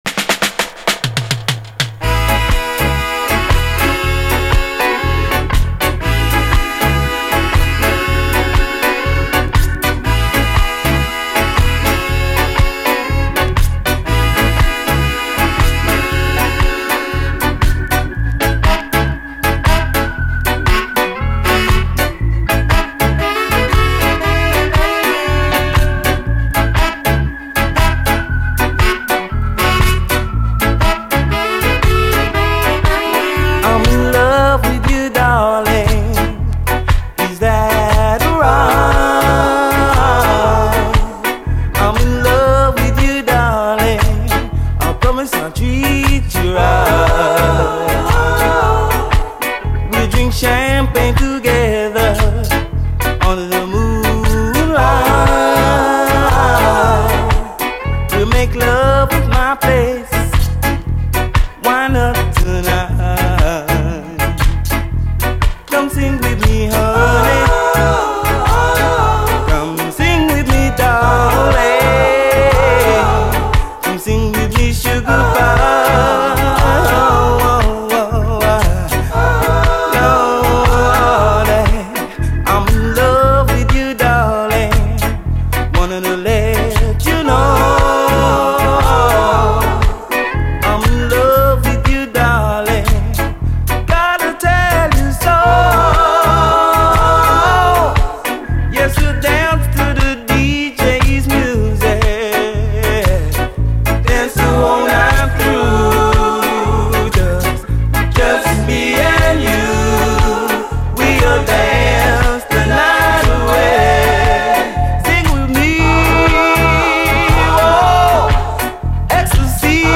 REGGAE
清涼コーラスが爽やかなUKラヴァーズ・クラシック！